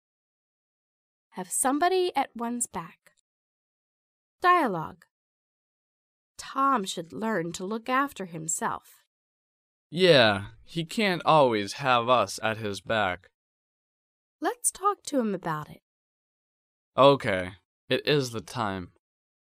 第一， 迷你对话